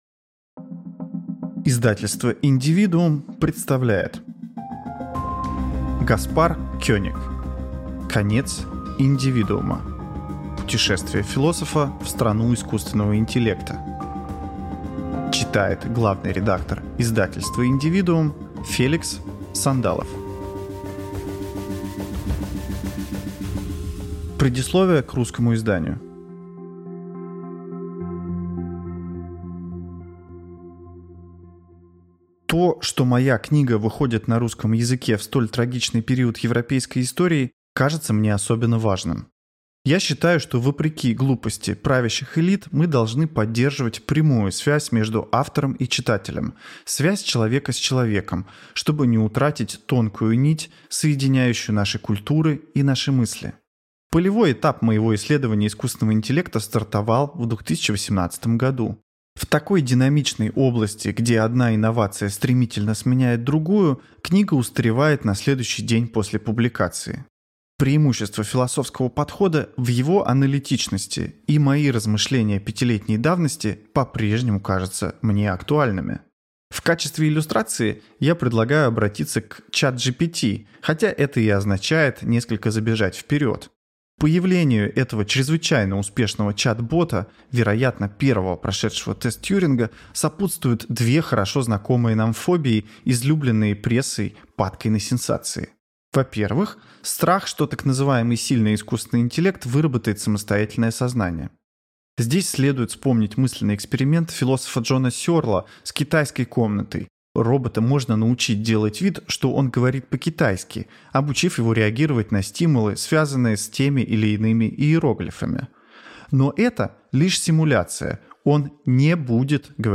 Аудиокнига Конец индивидуума. Путешествие философа в страну искусственного интеллекта | Библиотека аудиокниг